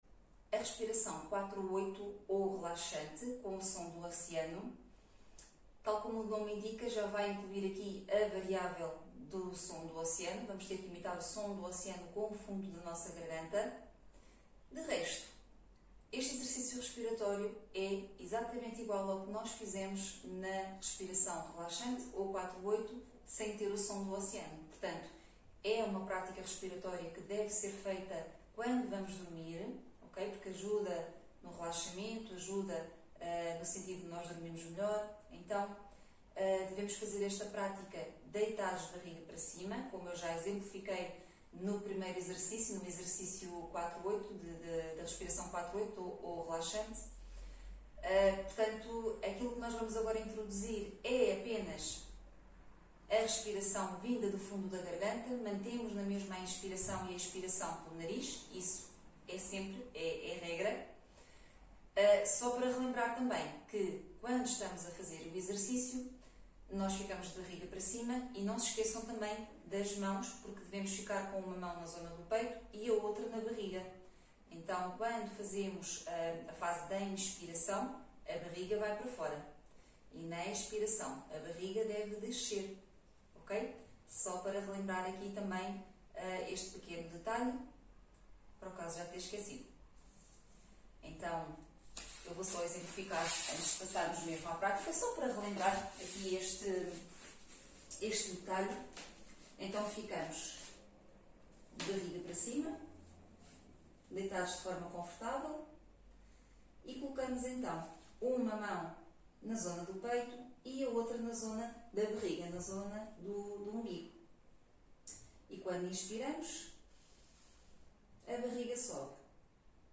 Respiracao-4-8-com-o-som-do-oceano
Respiracao-4-8-com-o-som-do-oceano.mp3